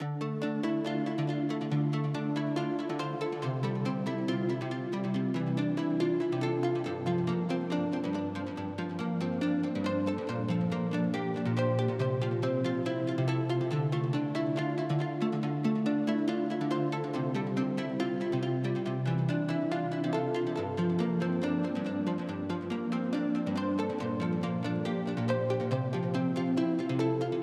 BLACKHEART_guitar.wav